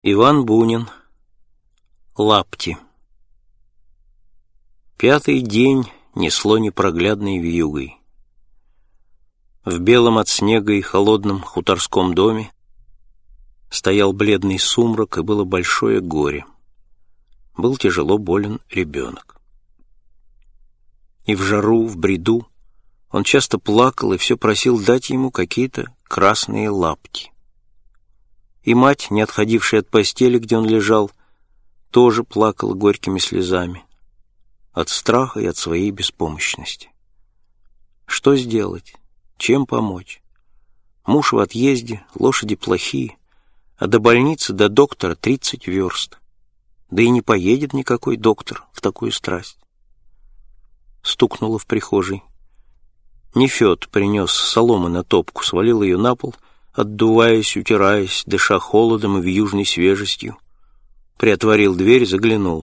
Аудиокнига Классика русского рассказа № 1 | Библиотека аудиокниг